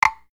Golf_Hit_Barrier_3.ogg